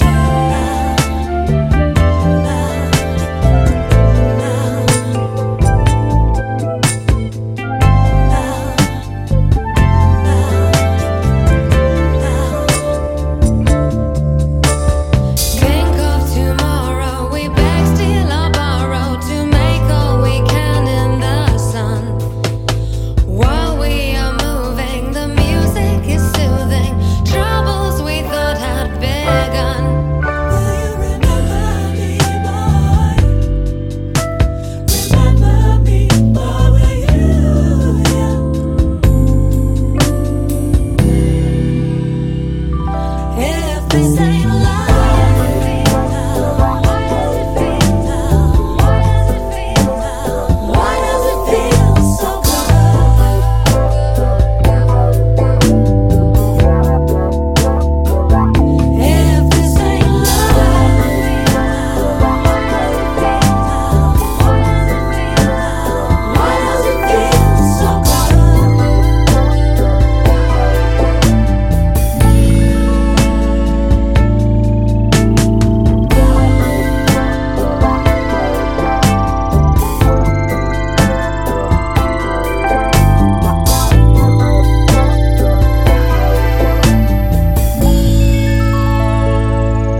ジャンル(スタイル) DISCO HOUSE / DEEP HOUSE / POP